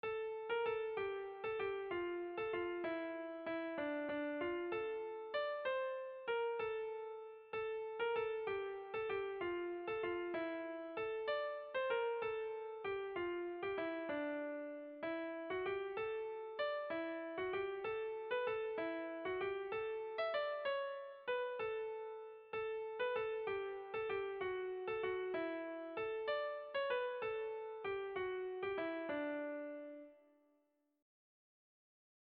Gabonetakoa
Zortziko handia (hg) / Lau puntuko handia (ip)
A1A2BA2